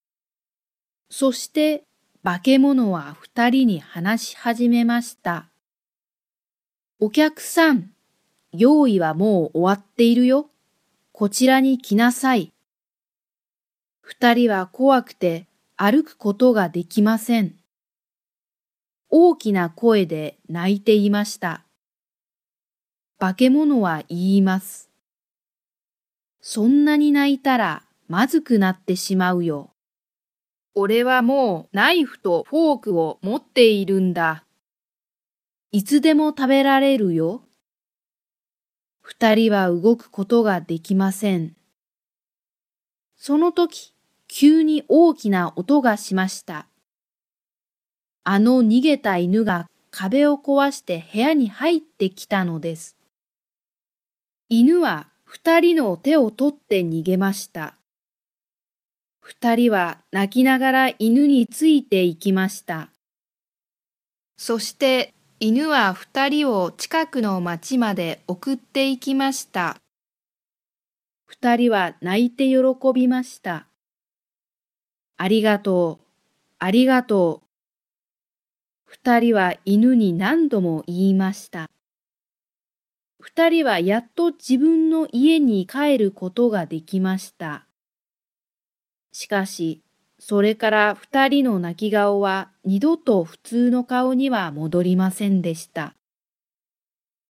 Japanese Graded Readers: Fairy Tales and Short Stories with Read-aloud Method
Natural Speed